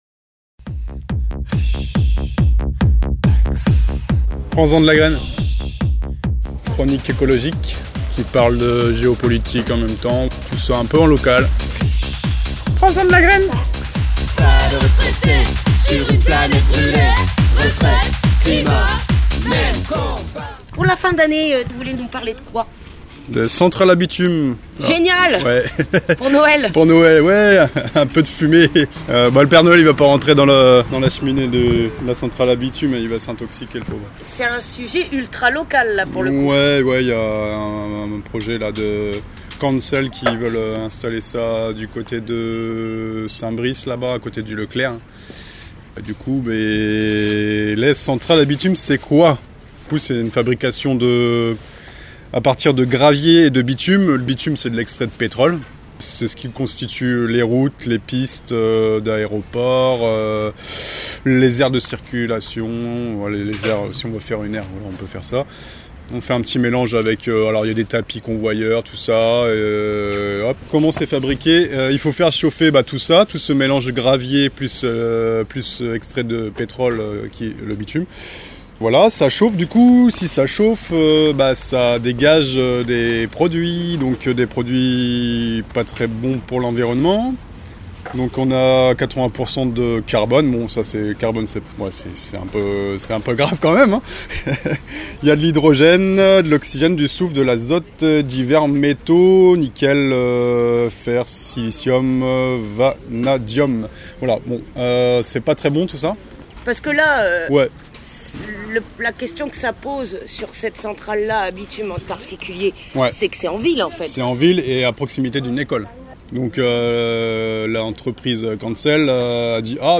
Chronique du mois de décembre (8:15)